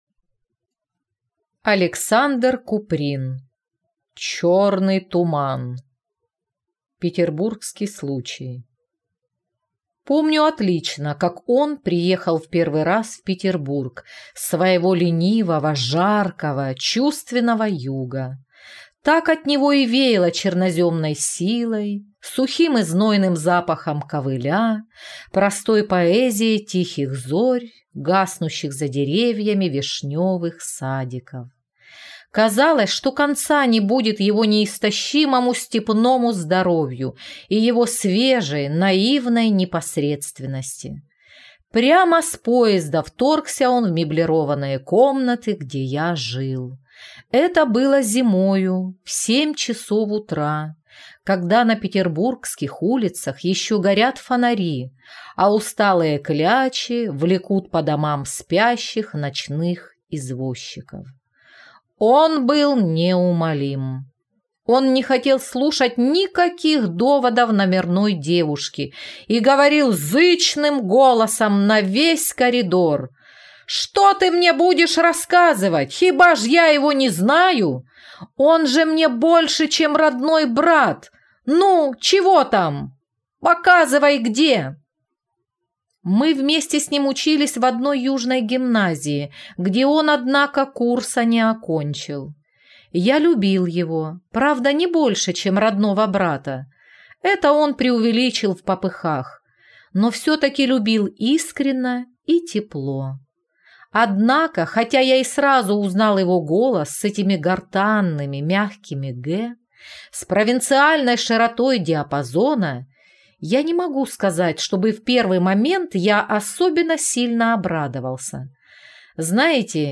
Аудиокнига Черный туман | Библиотека аудиокниг